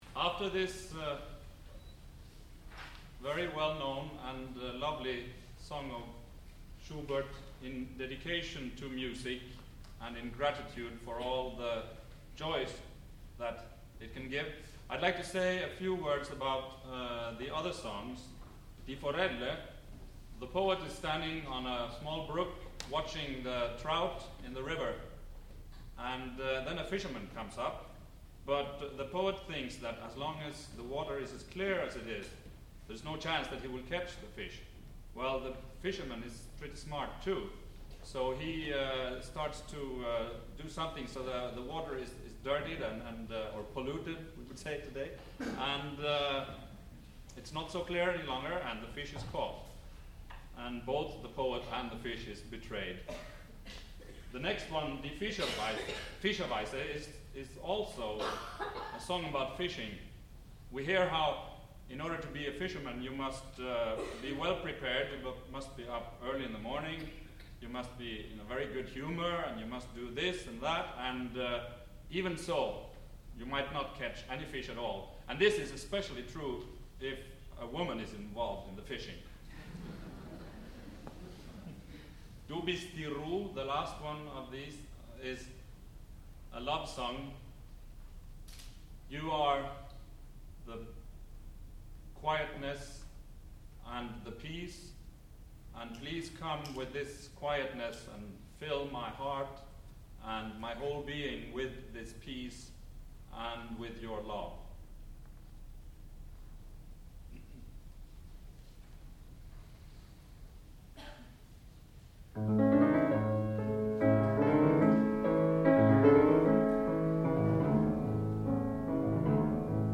classical music
baritone
piano